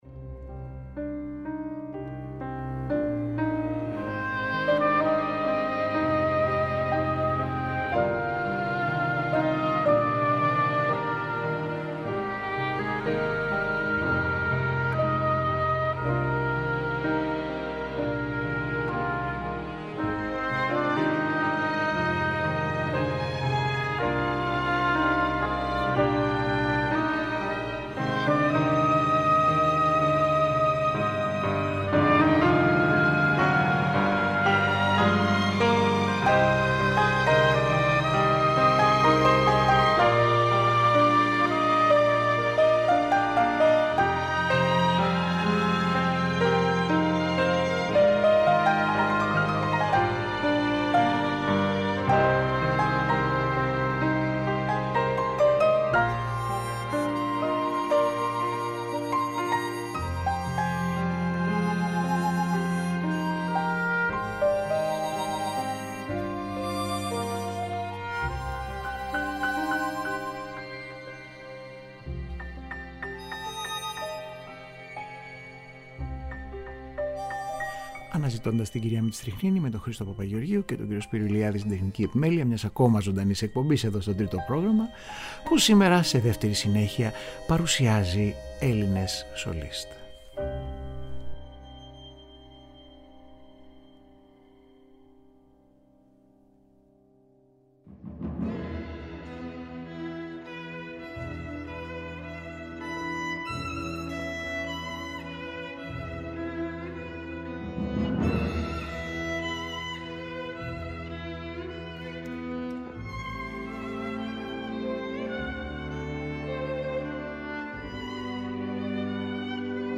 Σύγχρονοι Έλληνες σολίστ -γνωστοί και άγνωστοι- σε απρόσμενα εντυπωσιακές άγνωστες στο ευρύ κοινό ηχογραφήσεις είτε σε στούντιο από γνωστές εταιρίες ή ακόμα από ζωντανές εμφανίσεις.